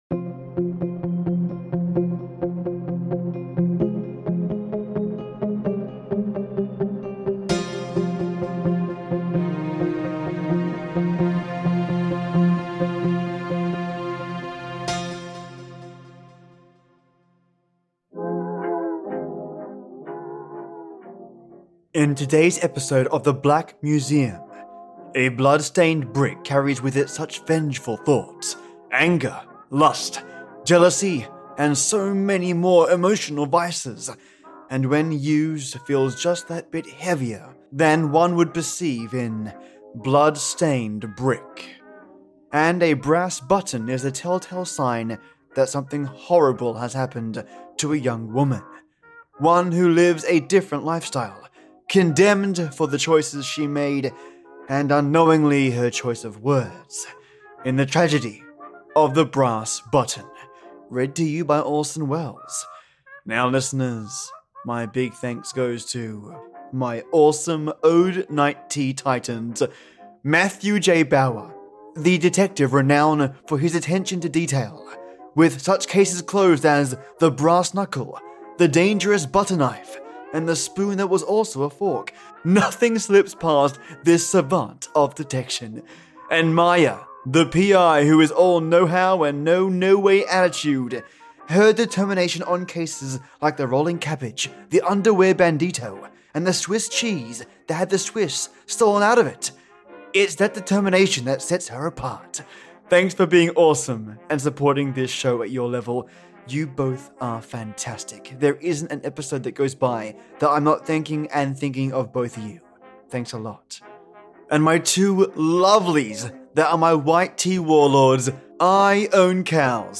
And a brass button is the tell tale sign that something horrible has happened to a young woman, one who lives a different life style, condemned for choices, and unknowingly, her choice of words…in the tragedy of The Brass Button, read to you by Orson Welles.